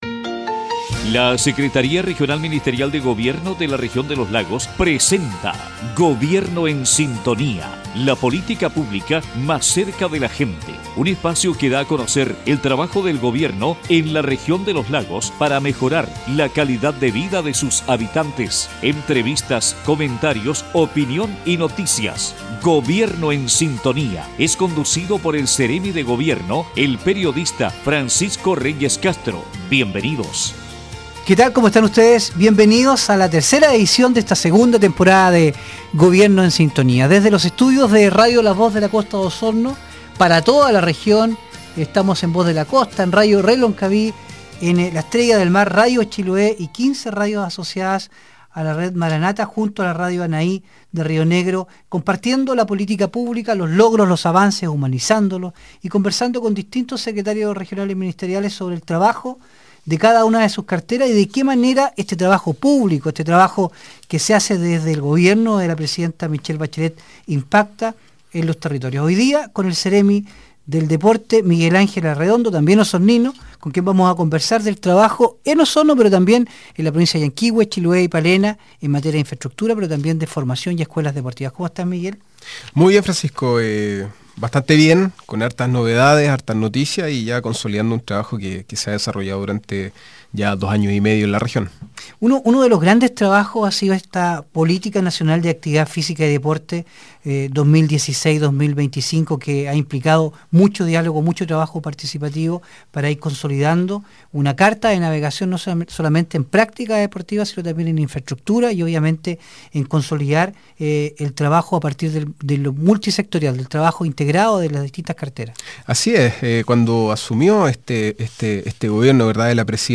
En conversación con Gobierno en Sintonía el Seremi del Deporte Miguel Arredondo dio a conocer los distintos planes que se ejecutan desde el Gobierno para impulsar el deporte de alto rendimiento en nuestra región.